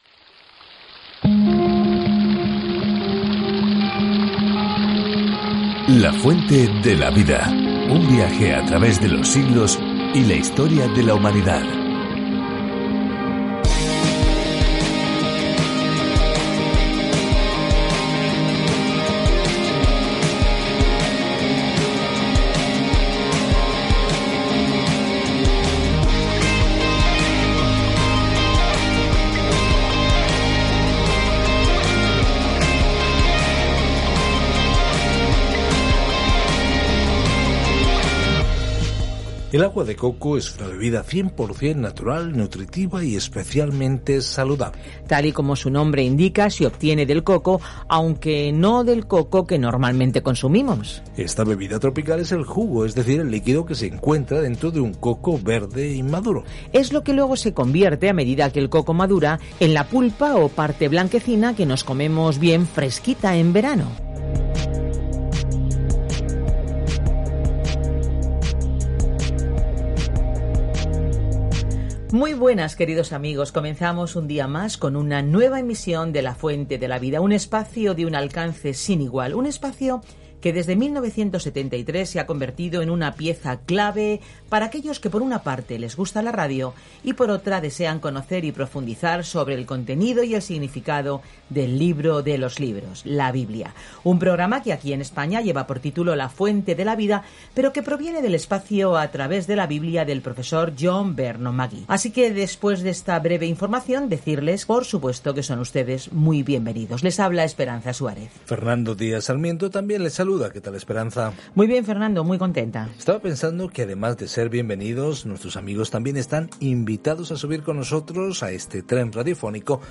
Escritura ZACARÍAS 10:7-12 ZACARÍAS 11:1-6 Día 25 Iniciar plan Día 27 Acerca de este Plan El profeta Zacarías comparte visiones de las promesas de Dios para dar a las personas una esperanza en el futuro y las insta a regresar a Dios. Viaja diariamente a través de Zacarías mientras escuchas el estudio en audio y lees versículos seleccionados de la palabra de Dios.